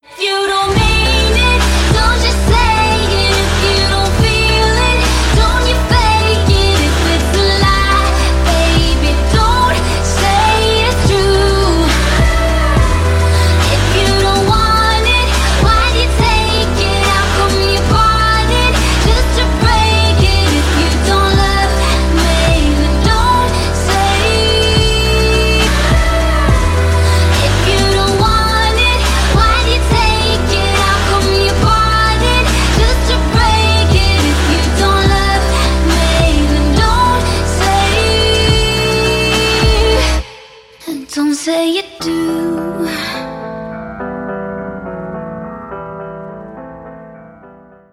• Качество: 320, Stereo
красивые
попса